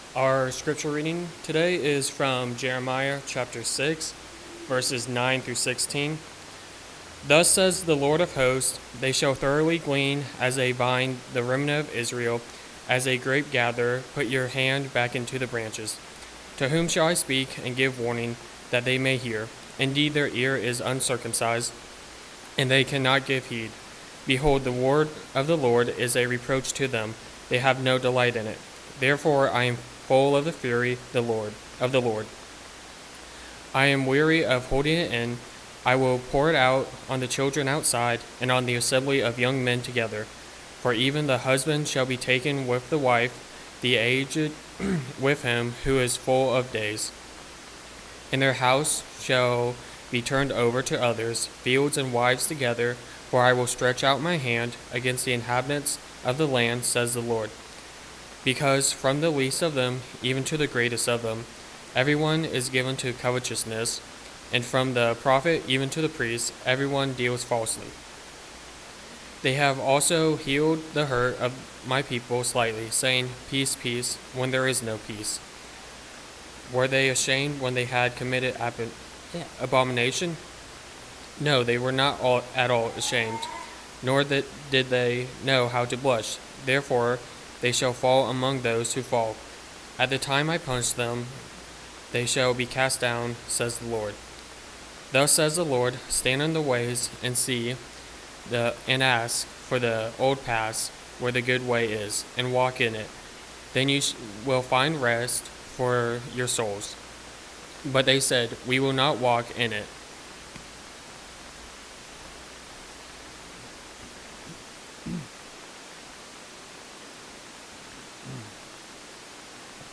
Jeremiah 6:9-16 Service Type: Sunday AM Topics